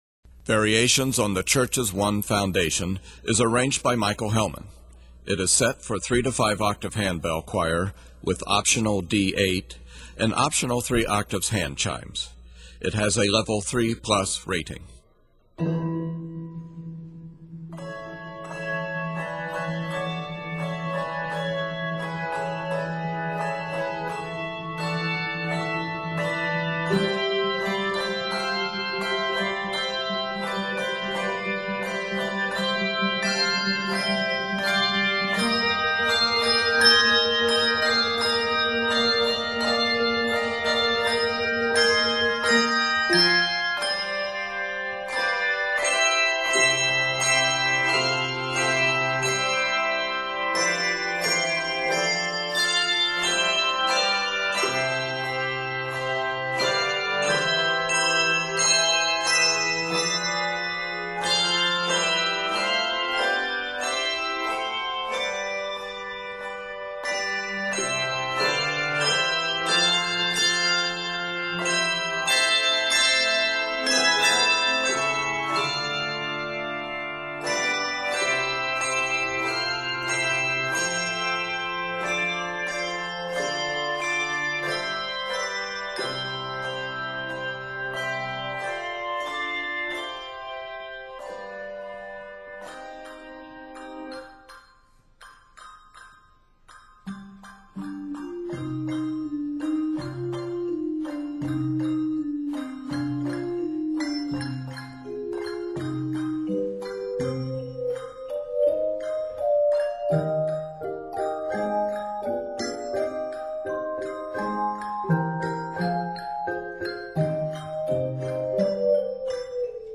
Bold fanfare ringing begins this work
scored in C Major and c minor
Octaves: 3-5